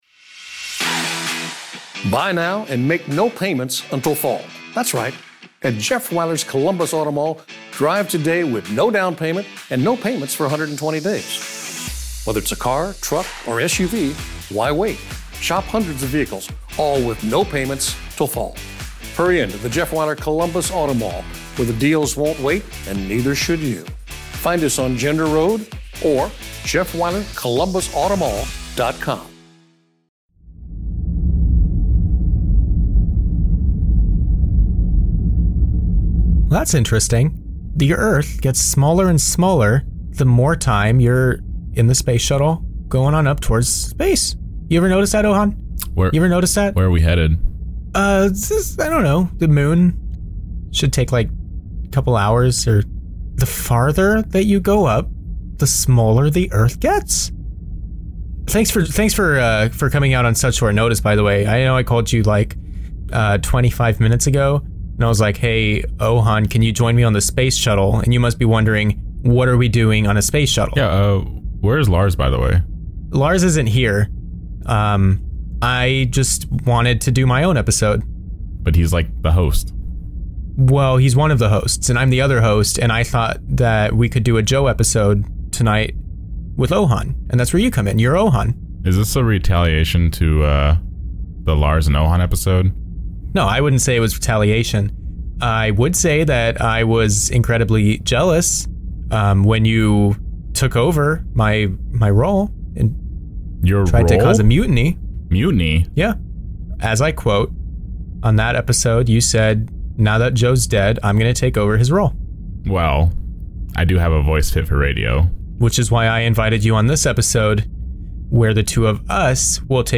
This episode was recorded live on our Discord.